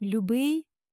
lyoo-BYY dear (to a man)